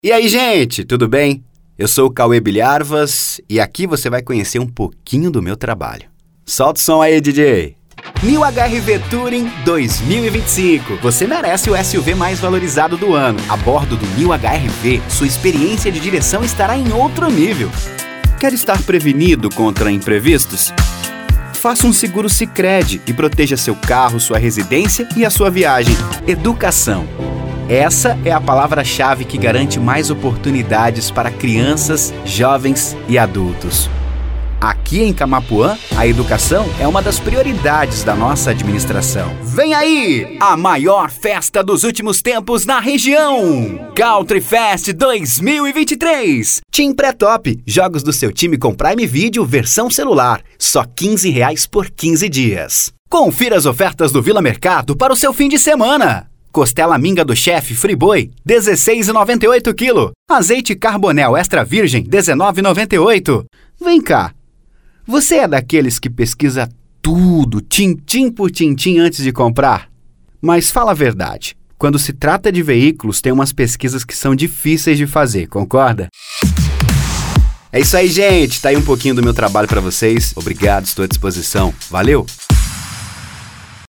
Spot Comercial
Padrão
Animada